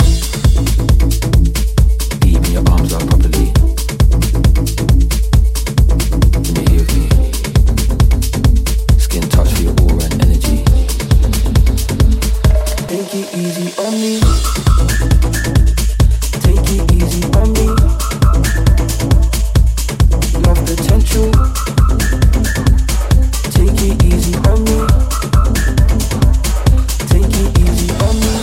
Genere: pop,dance,afrobeat,house.remixhit